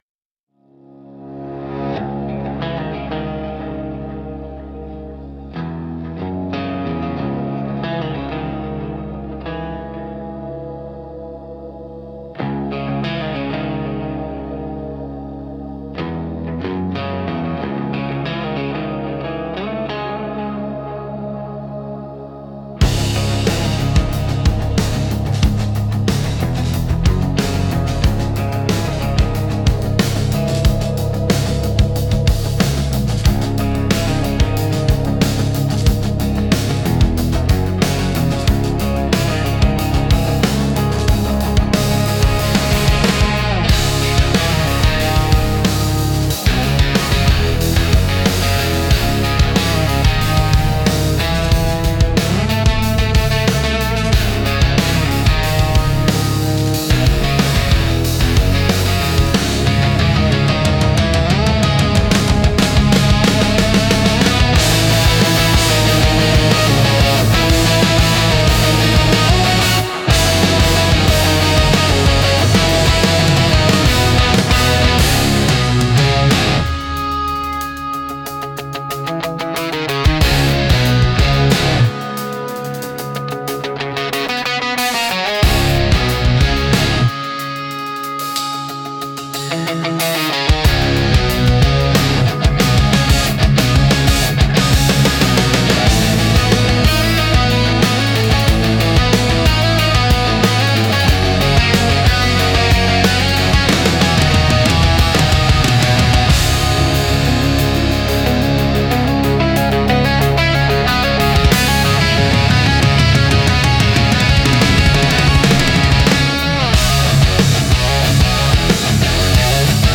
Instrumental - A Prayer for Wanderers 4.46